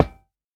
latest / assets / minecraft / sounds / block / iron / break3.ogg